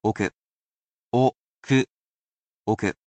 He’ll be here to help sound out these vocabulary words for you.
He’s lovely with tones, as well, and he will read each mora so you can spell it properly in kana.